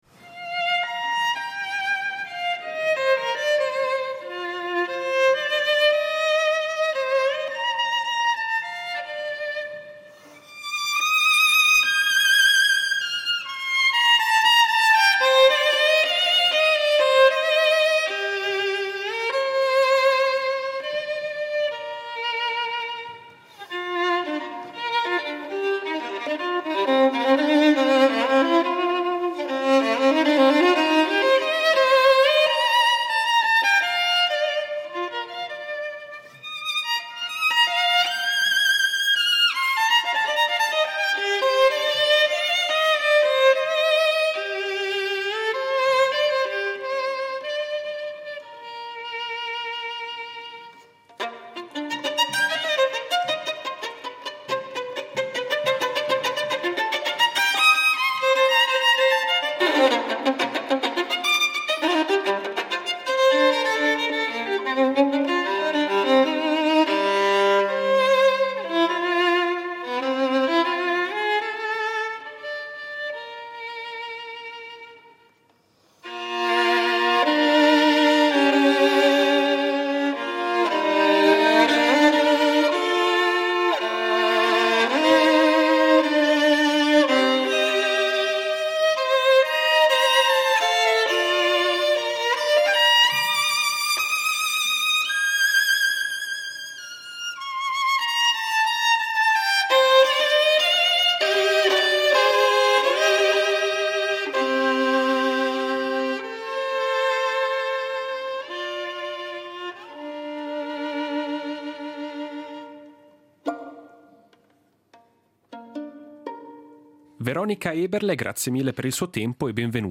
Incontro con Veronika Eberle